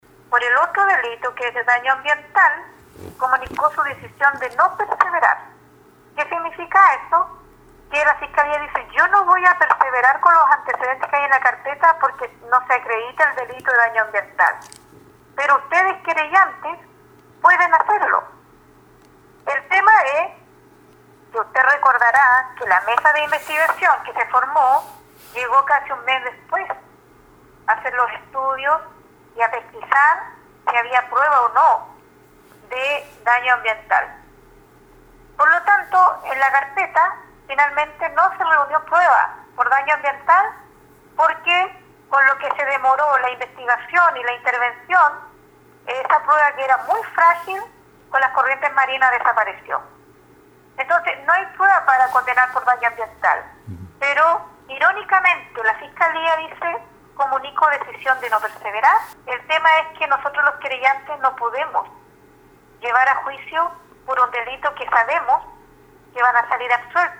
En entrevista con radio Estrella del Mar dijo que esta situación es grave dado que las acciones del ministerio público buscan dejar sin responsabilidades civiles ni políticas el alto daño ambiental y económico que generó el desastre ambiental en la región desde 2016.